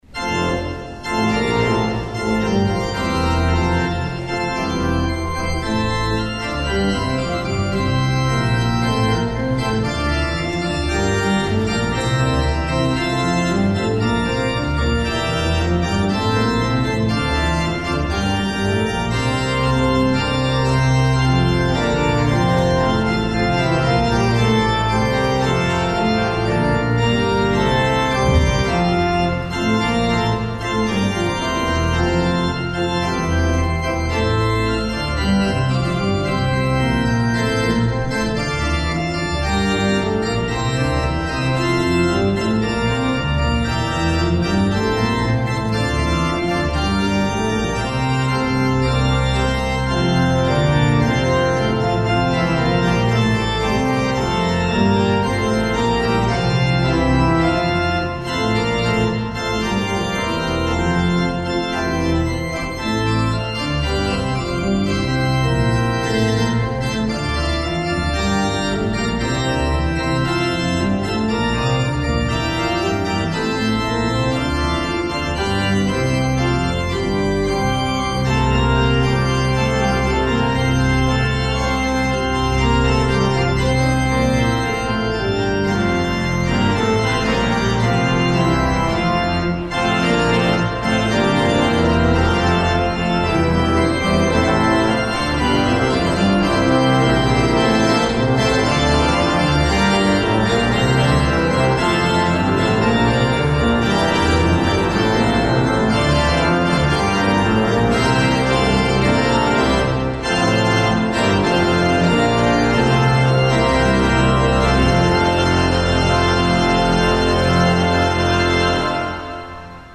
Hear the Bible Study from St. Paul's Lutheran Church in Des Peres, MO, from December 7, 2025.